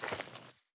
Mud_Mono_02.wav